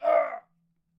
argh2.ogg